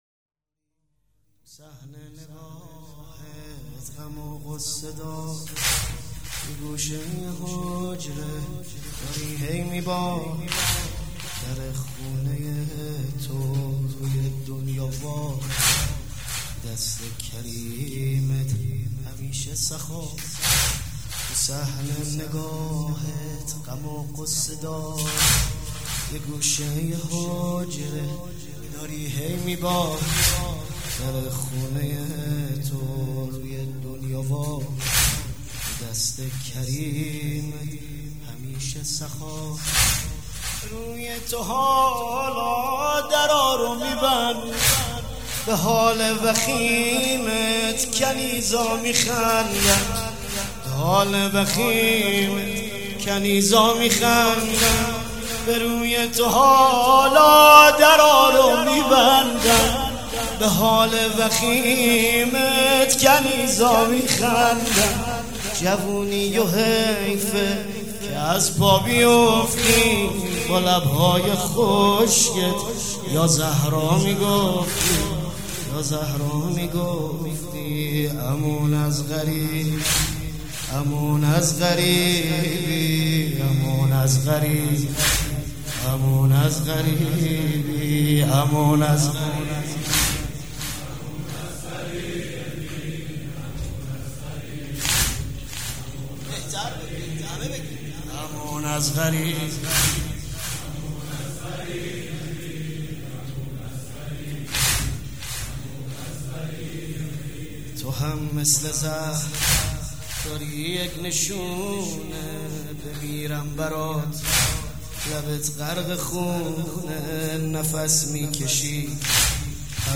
مداح
شهادت امام جواد (ع)